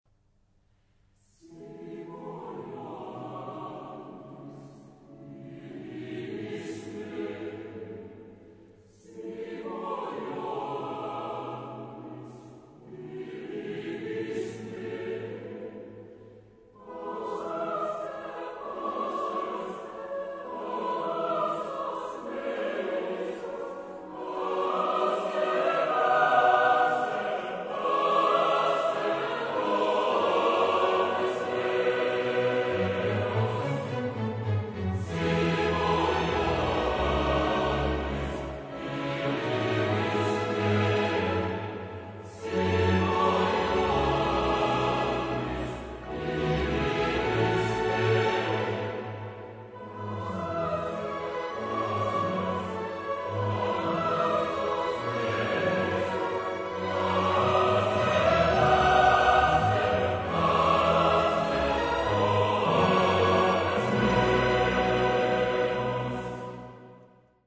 Tu es Petrus déroule l’une des plus superbes mélodies du compositeur, où la séduction de la courbe s’enrichit du rythme calme d’un hymne.